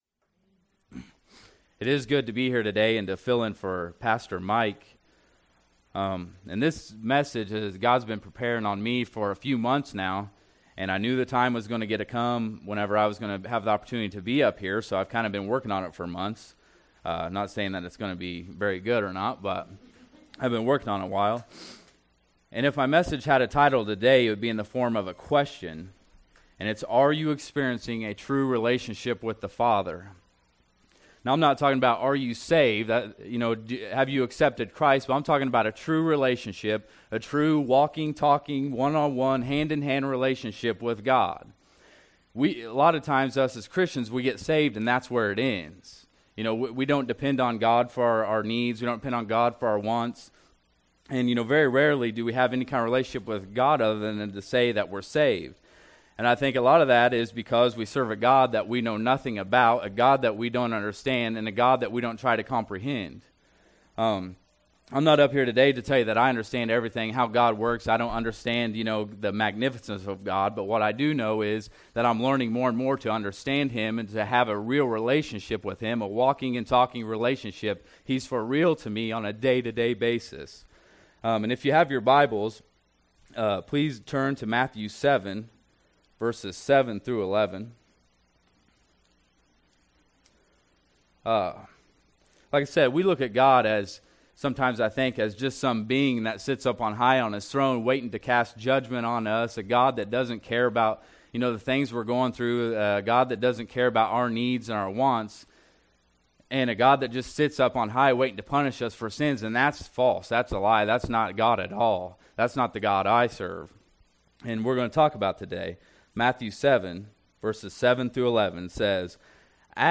"Matthew 7:7-11" Service Type: Sunday Morning Worship Service Bible Text